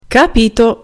Now listen to the intonation of someone who replies to the above question: